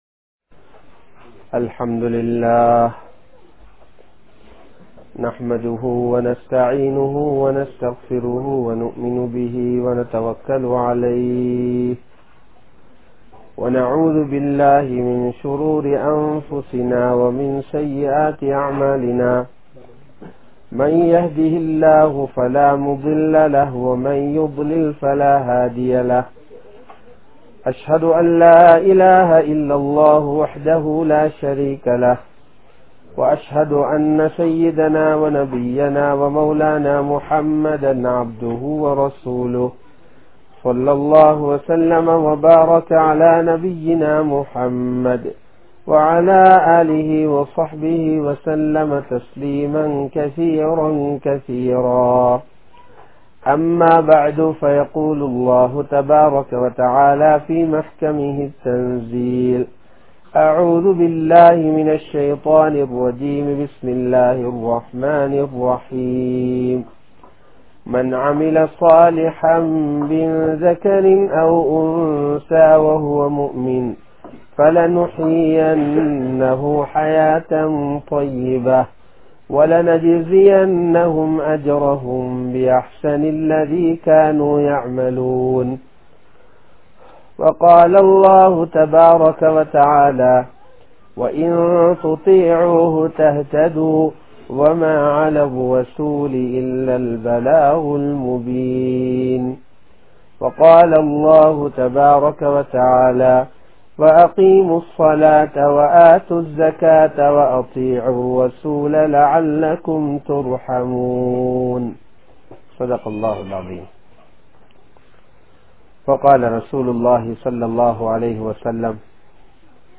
Ivvulaha Vaalkai Vaalkaiyalla (இவ்வுலக வாழ்க்கை வாழ்க்கையல்ல) | Audio Bayans | All Ceylon Muslim Youth Community | Addalaichenai